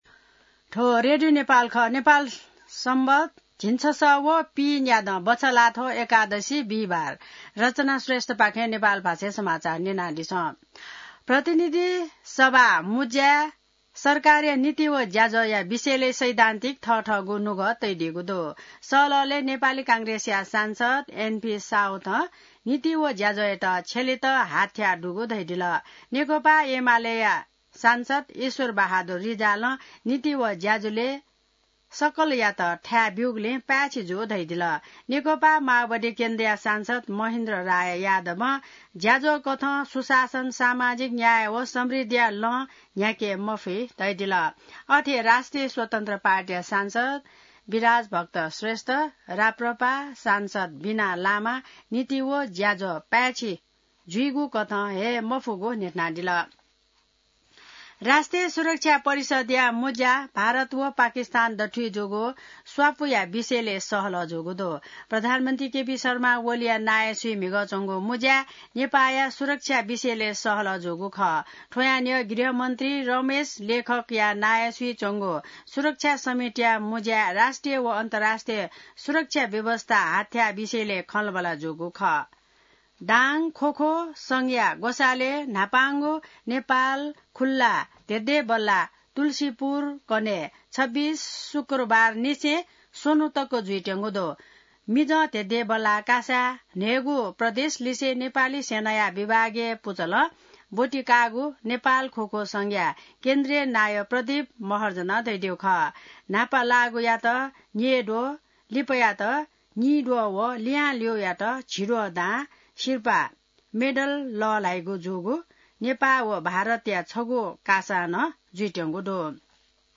नेपाल भाषामा समाचार : २५ वैशाख , २०८२